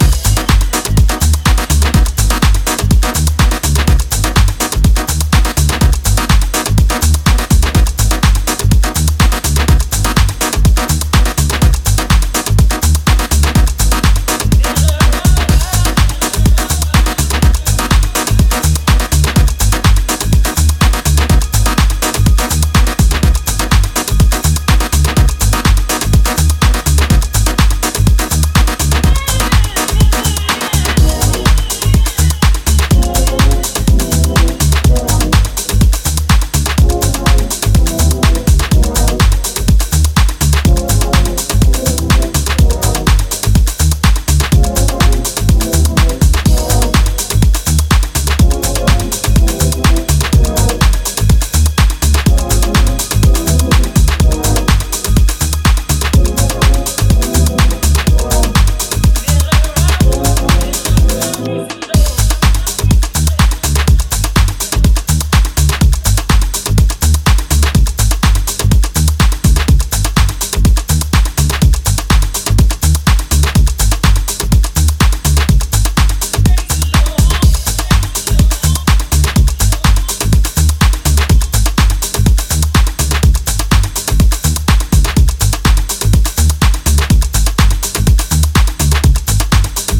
> HOUSE・TECHNO
ジャンル(スタイル) DEEP HOUSE / HOUSE